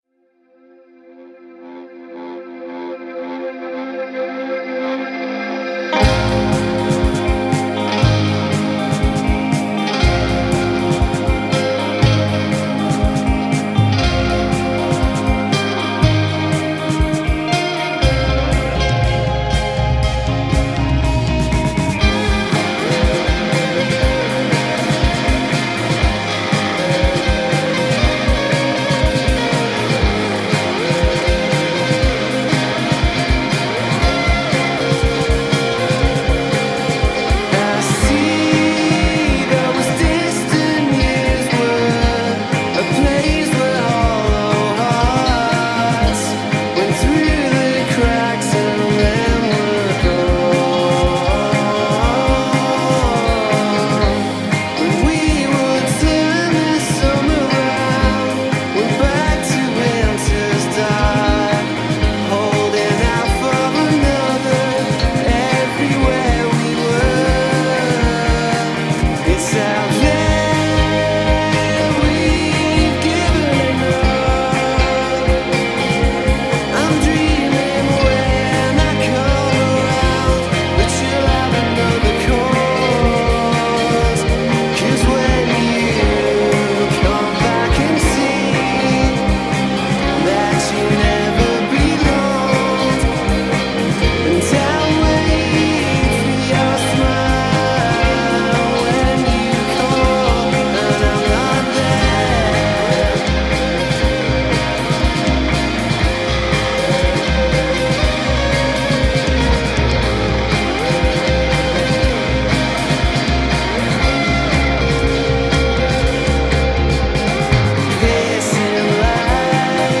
Dream pop quartet